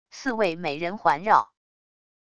四位美人环绕wav音频